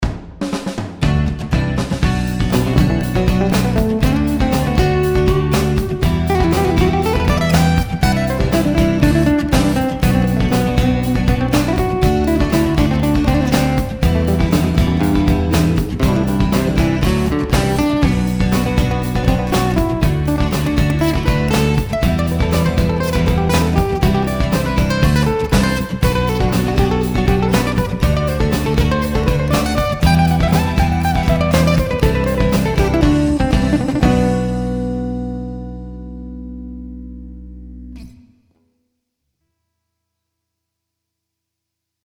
音楽ジャンル： 民族
楽曲の曲調： MIDIUM
シリアス